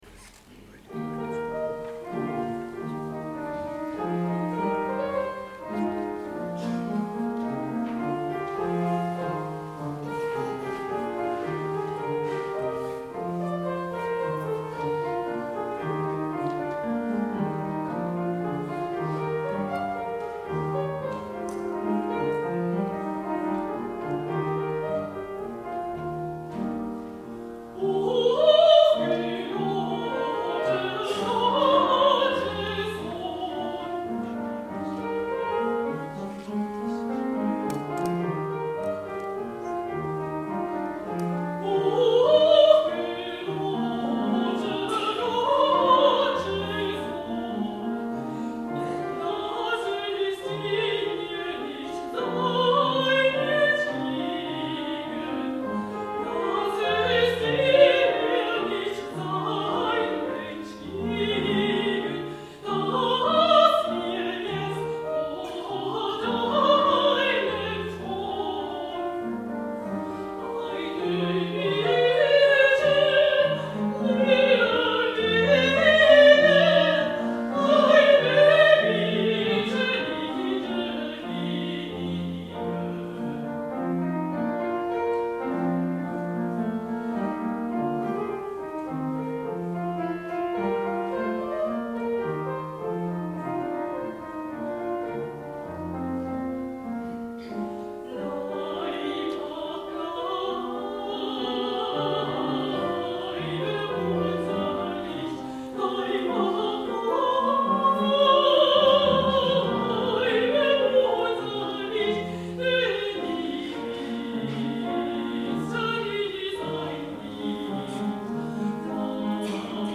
Repertoire – molto vocalis – Der Mössinger Chor
Durch Zeit und Ewigkeit, Mössingen 2024